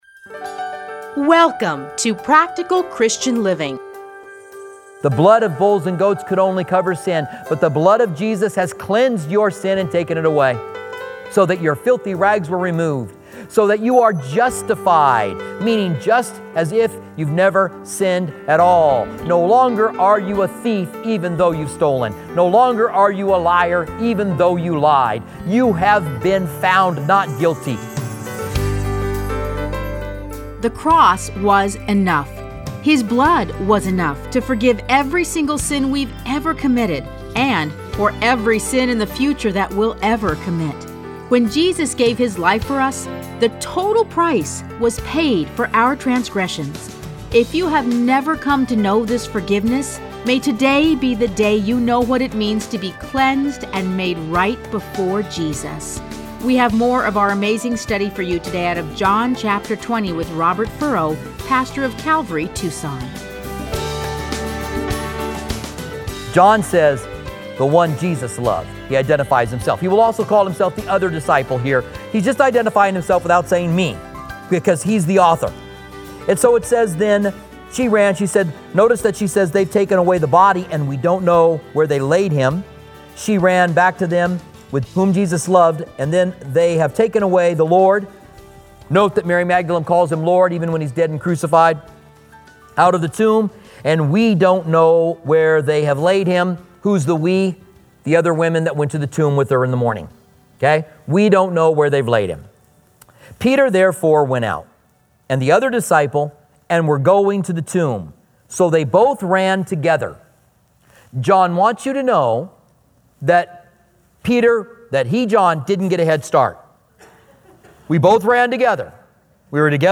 Listen to a teaching from John 20:1-18.